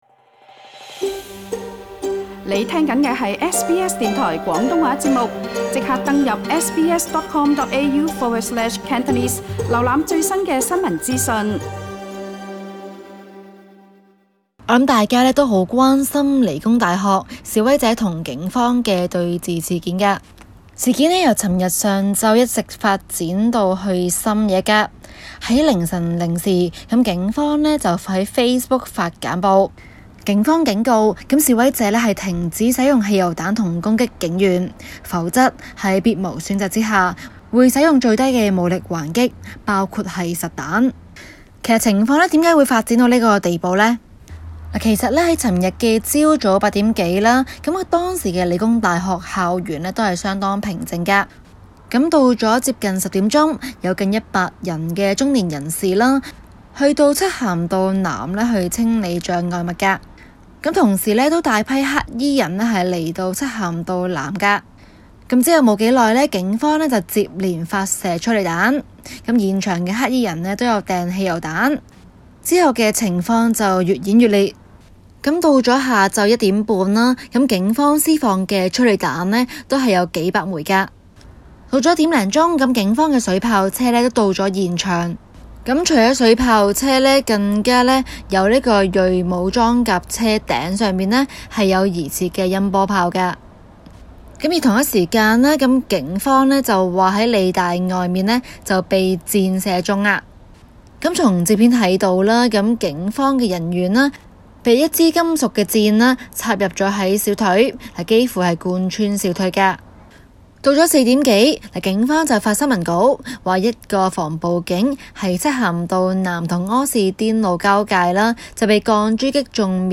Source: AP SBS廣東話節目 View Podcast Series Follow and Subscribe Apple Podcasts YouTube Spotify Download (11.6MB) Download the SBS Audio app Available on iOS and Android 「中港快訊」今天報導理工大學昨日爆發激烈示威衝突。